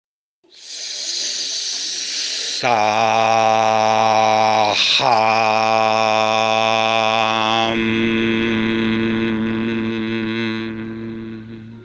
eseguire il mantra SAA’AAM con tonalità lenta bassa e grave e concentrandosi nel plesso cardiaco.